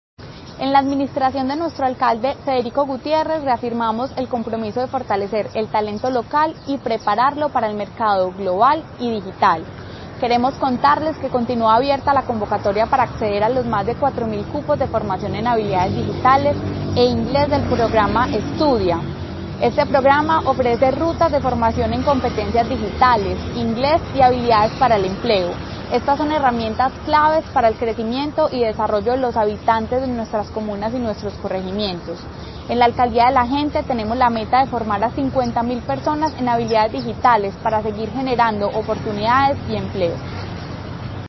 Declaraciones de la secretaria de Desarrollo Económico, María Fernanda Galeano Rojo